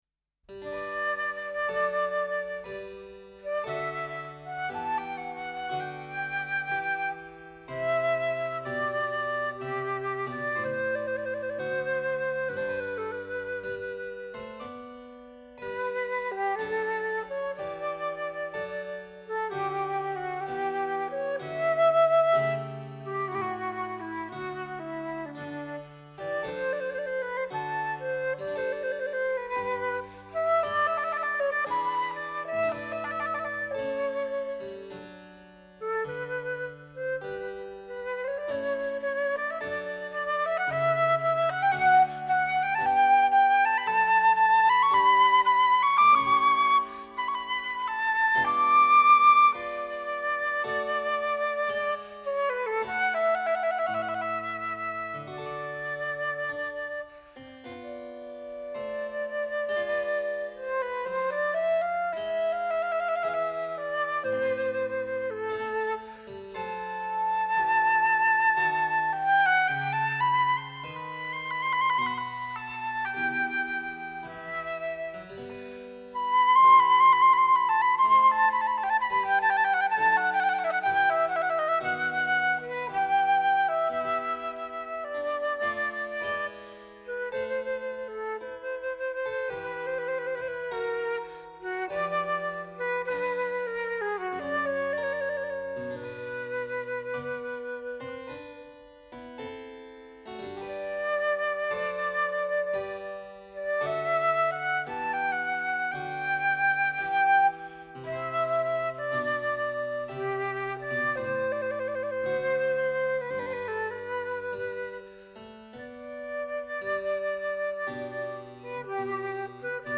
clavicembalo